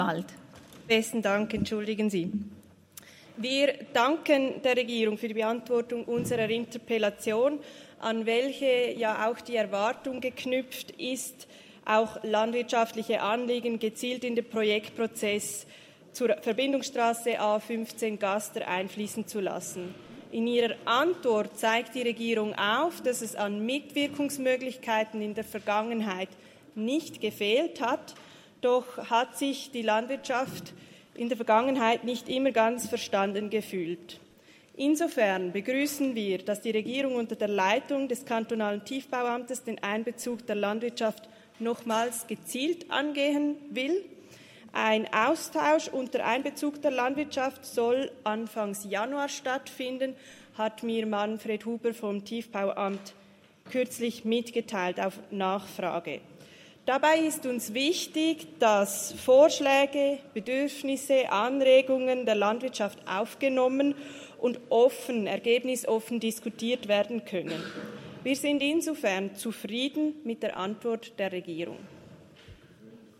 Session des Kantonsrates vom 19. bis 21. September 2022